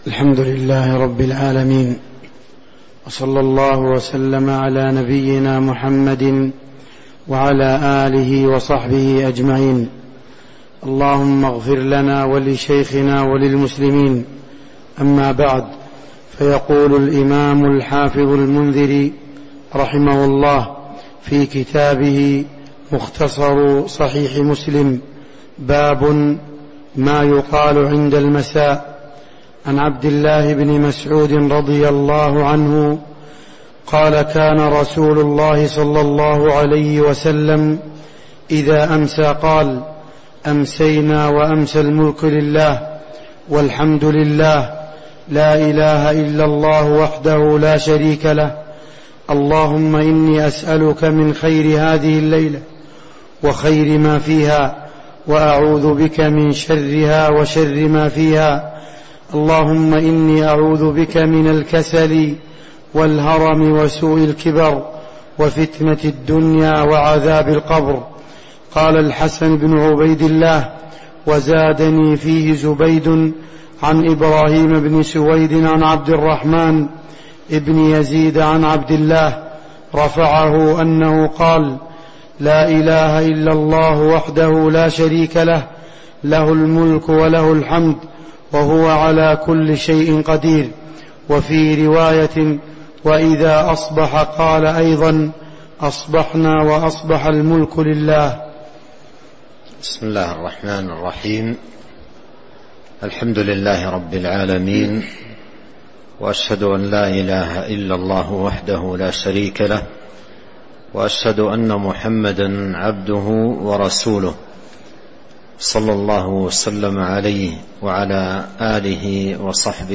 تاريخ النشر ٤ ذو الحجة ١٤٤٣ هـ المكان: المسجد النبوي الشيخ